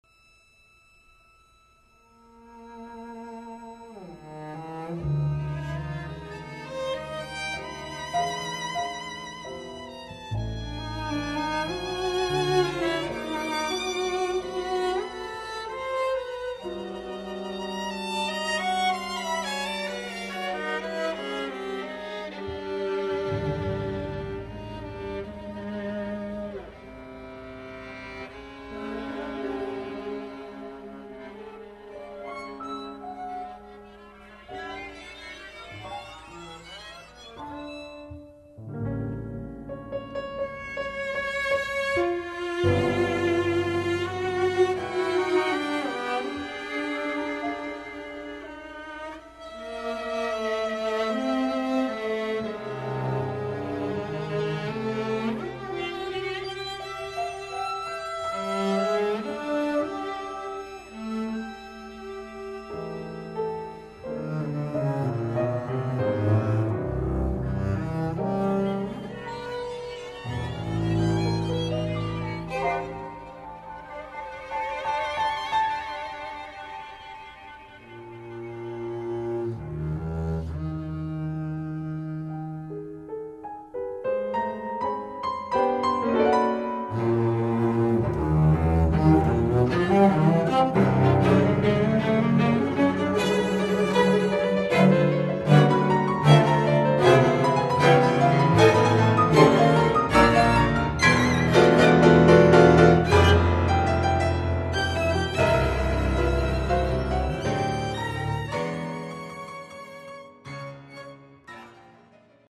Please note: These samples are not of CD quality.
Quintet for Piano and Strings